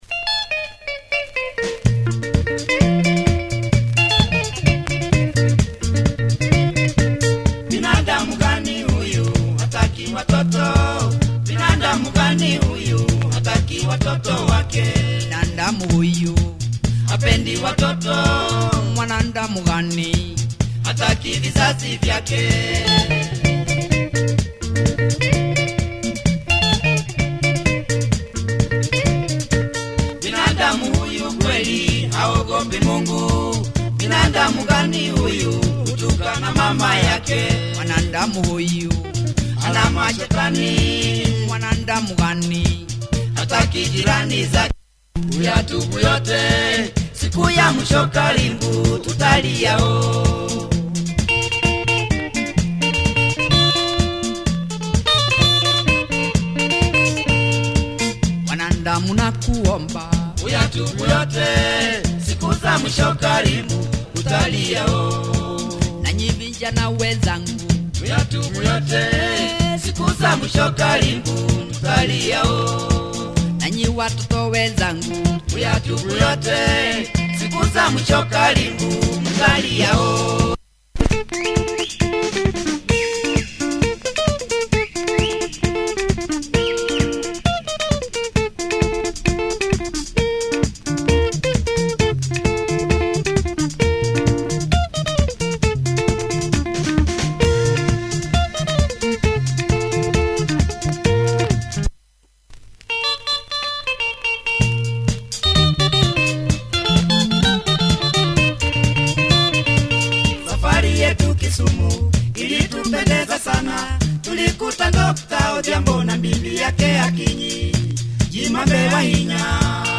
Nice Kikuyu Benga!